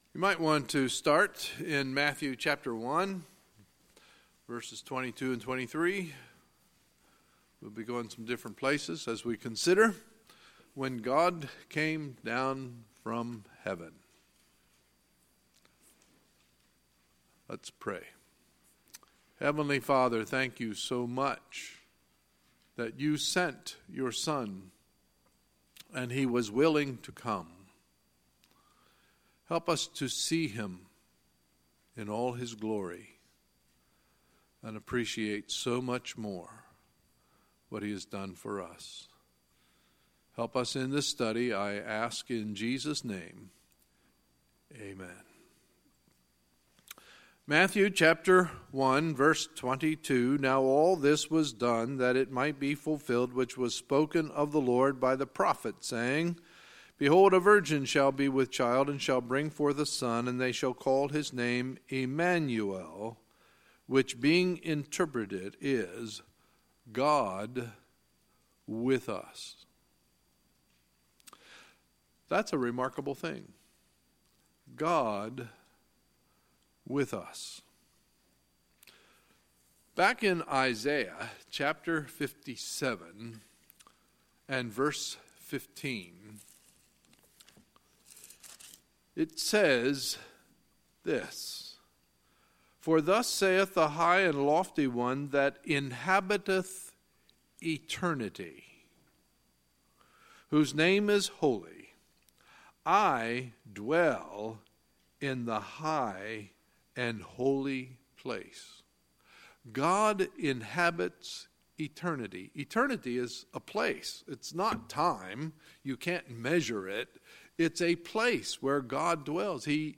Sunday, December 31, 2017 – Sunday Morning Service